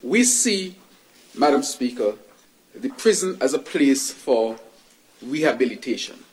That’s the voice of Prime Minister and Minister of National Security, Hon. Dr. Terrance Drew, as he led a debate on the Prison (Amendment) Bill, 2025, in the National Assembly on Thursday, May 15th, 2025.